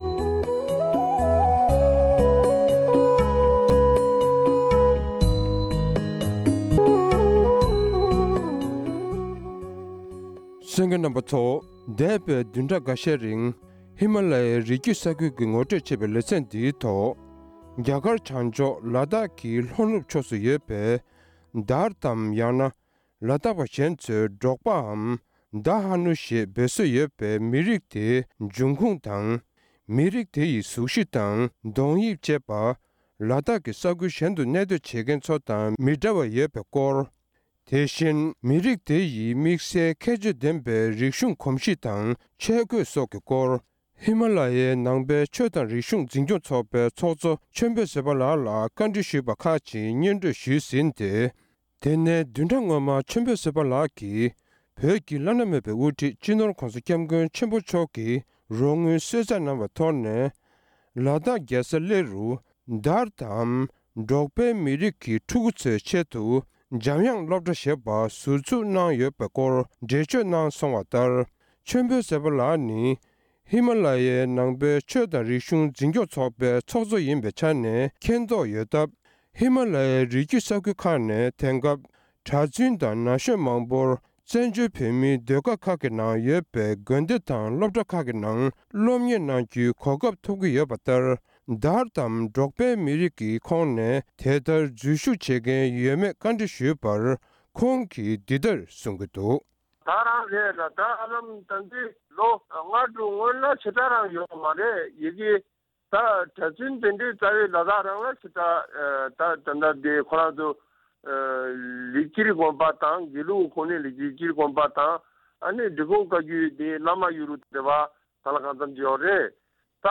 གནས་འདྲི་ཞུས་པ་ཞིག་